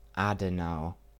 Adenau (German: [ˈaːdənaʊ]